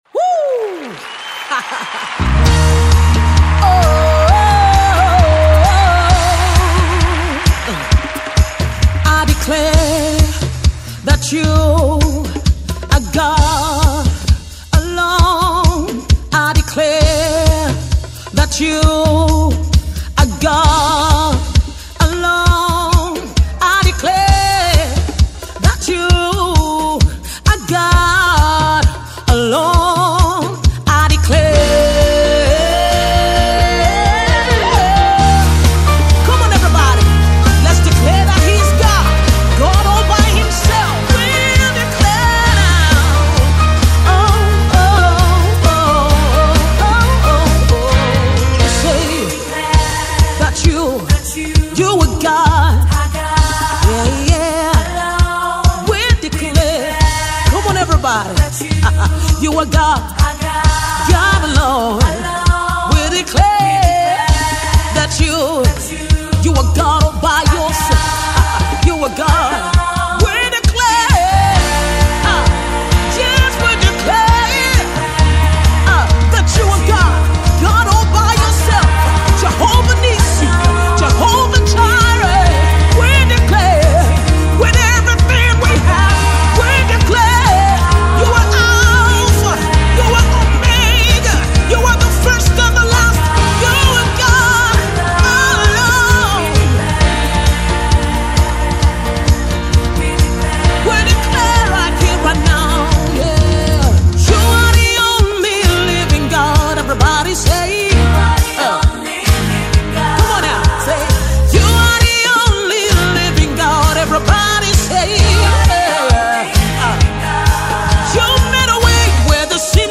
This medley is a remix